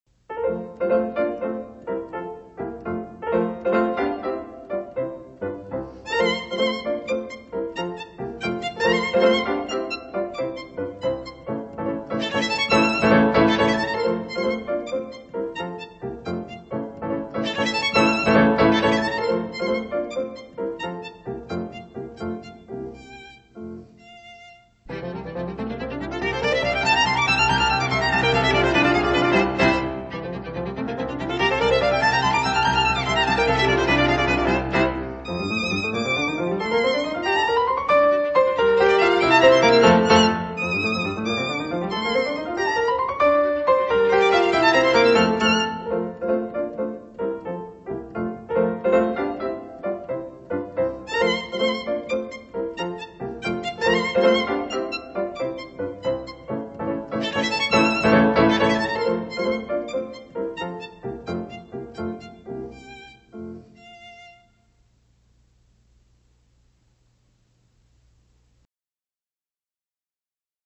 你现在听到的是第一乐章快板